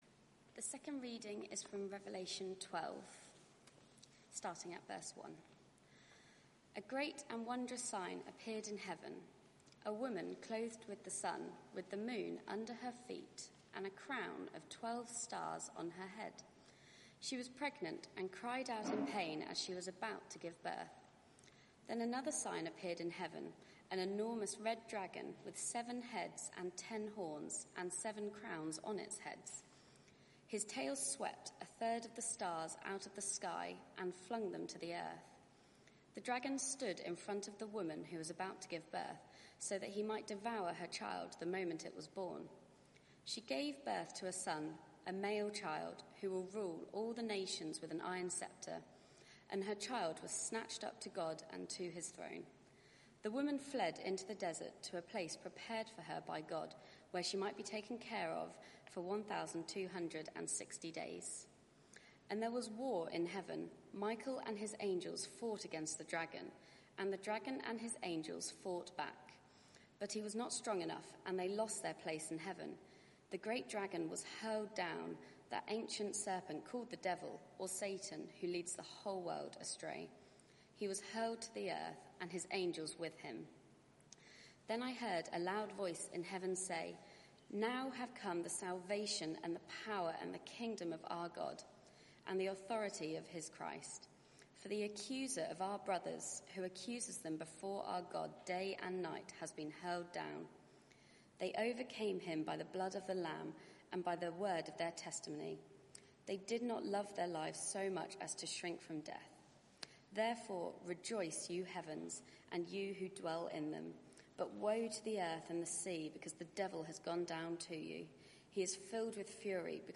Media for 6:30pm Service on Sun 06th Dec 2020 18:30 Speaker
The Dragon Slayer Sermon Search the media library There are recordings here going back several years.